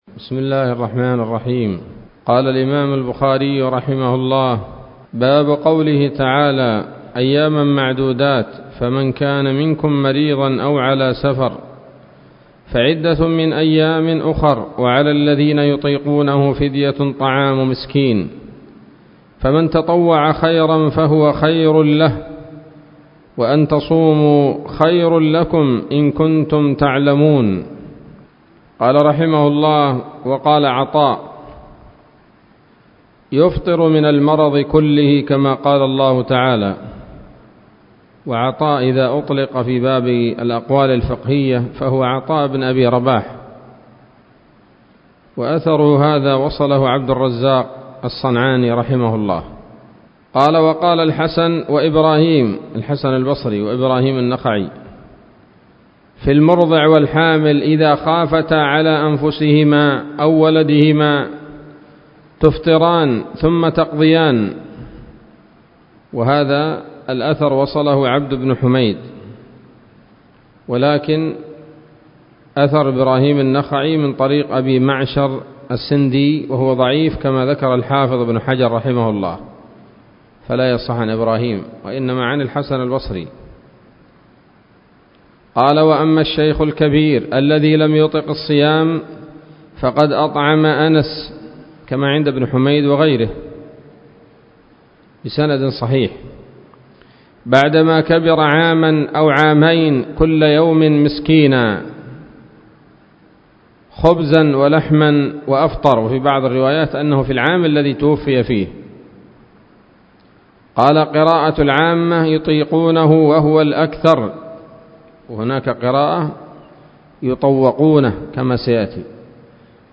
الدرس الثاني والعشرون من كتاب التفسير من صحيح الإمام البخاري